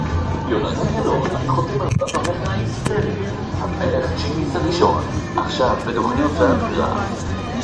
תמיד שעשעו אותי ה"הכרזות" עליי בכל פעם שהגעתי לחתום בדוכן. הקלטתי אחת מהן בטלפון הנייד (מתנצל על האיכות הירודה):
Announcement.wav